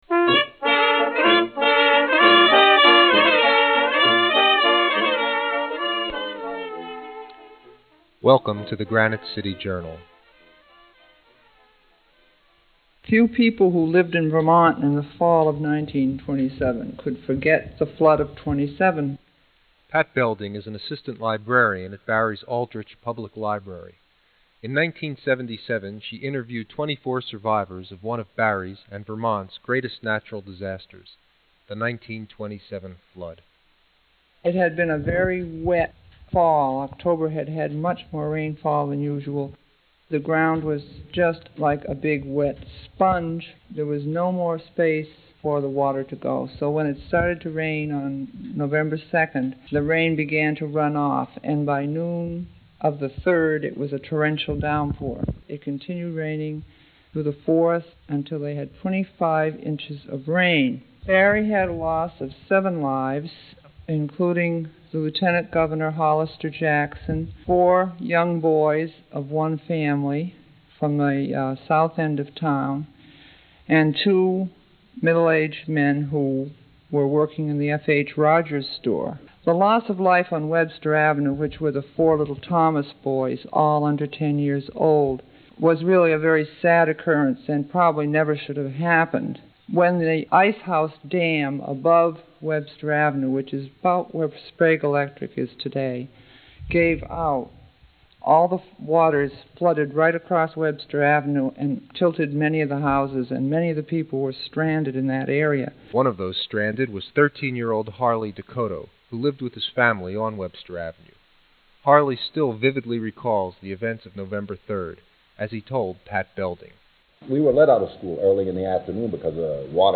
As a result, the audio quality of the interviews varies.